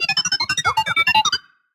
beeps1.ogg